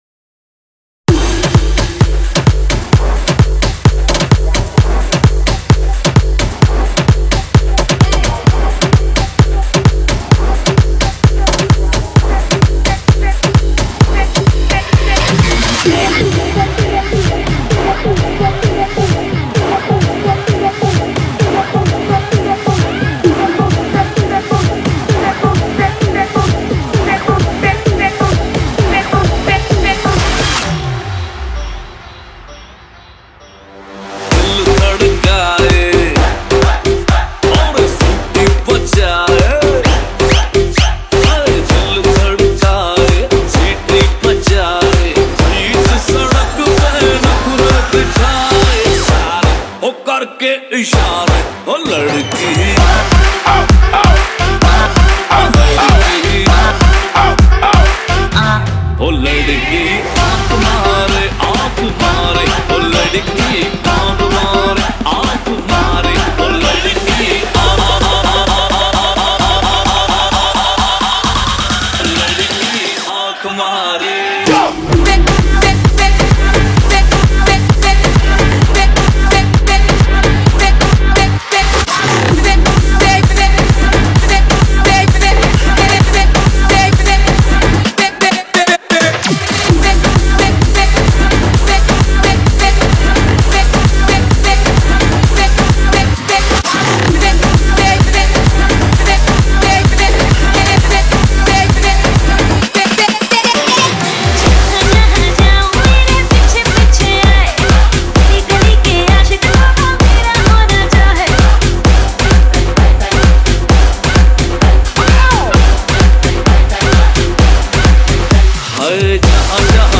Category: Latest Dj Remix Song